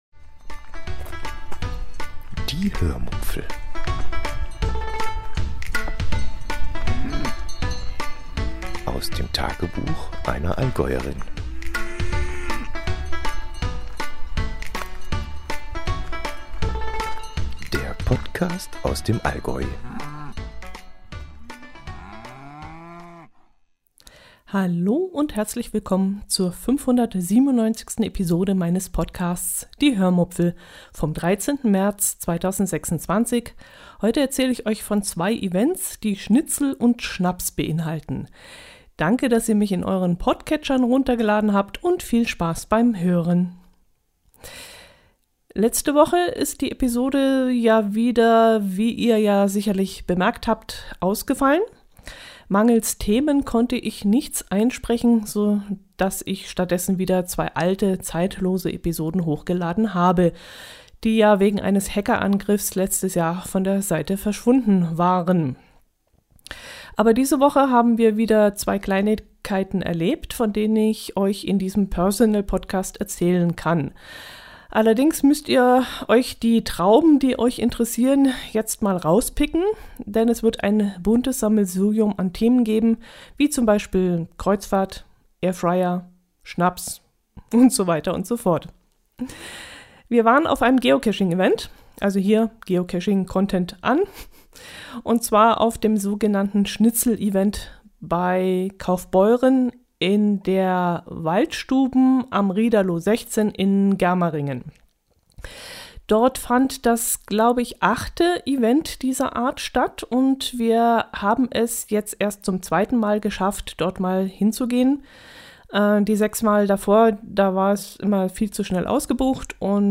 Zunächst nehme ich euch mit zum sogenannten „Schnitzel-Event“ in der Waldstub’n in Germaringen bei Kaufbeuren – ein inzwischen legendäres Geocaching-Treffen, das regelmäßig schnell ausgebucht ist.